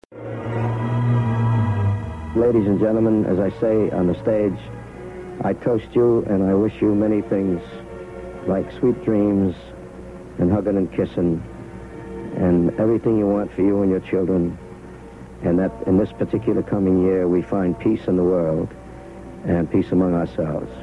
I don’t know who originated it but here’s the audio of Frank Sinatra saying something to the effect “In this upcoming year, may we find peace in the world and peace among ourselves.”